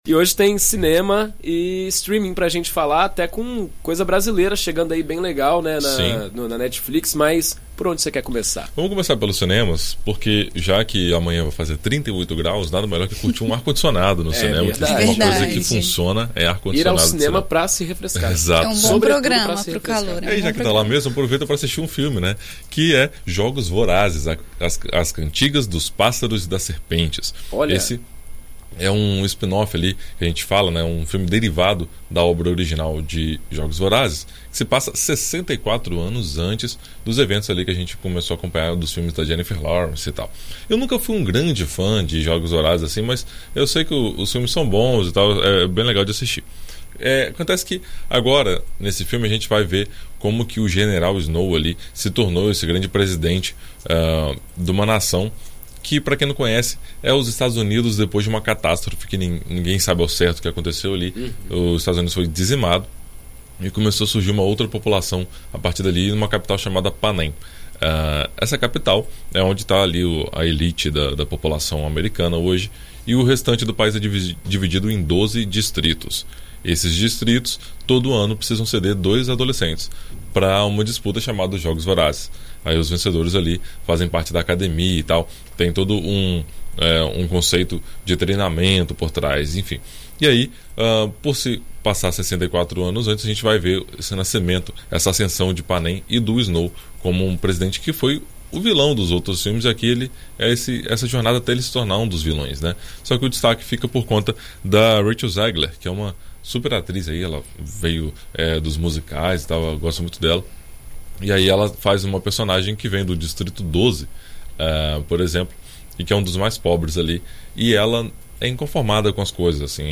Na coluna Sem Spoiler desta quinta-feira (19) na rádio BandNews FM ES